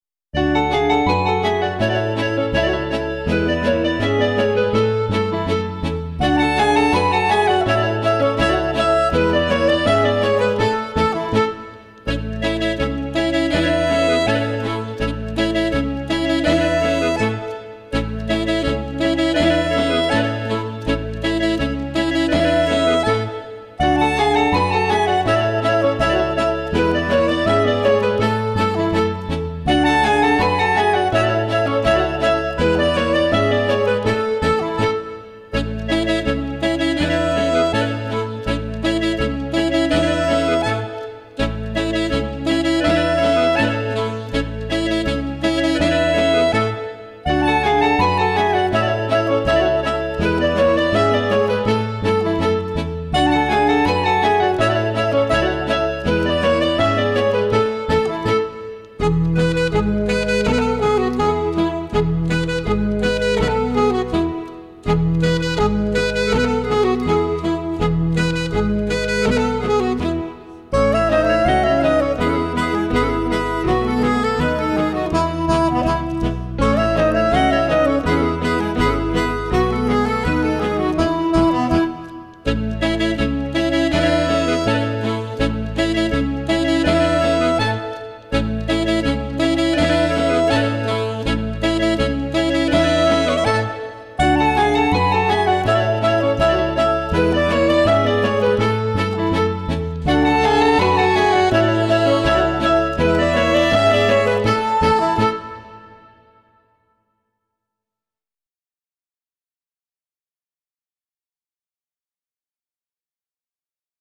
Kreistanz